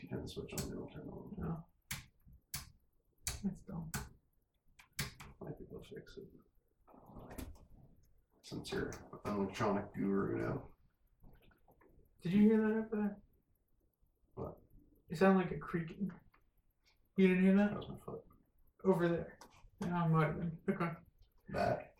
Recording 3 – EVP:
• A very similar sound of footsteps or creaks were heard at :11 as we discussed the Hope Doll.